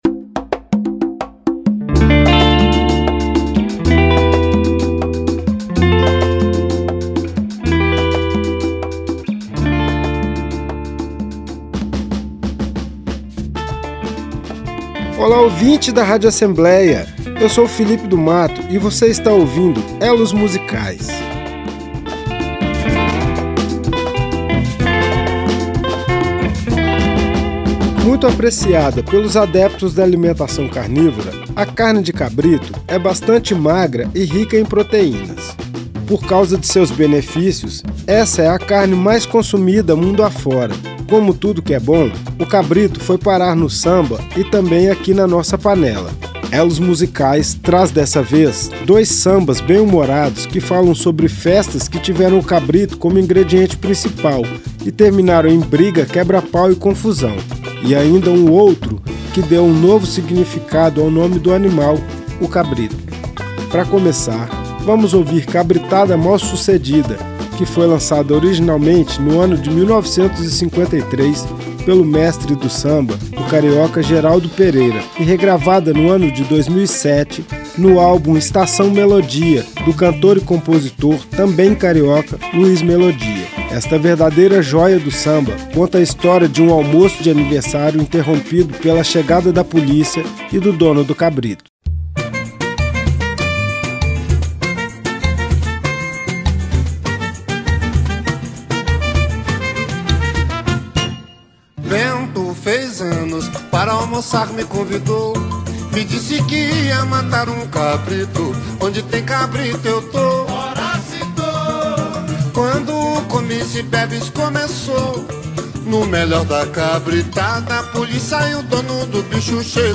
Nesta edição temos três sambas bem-humorados.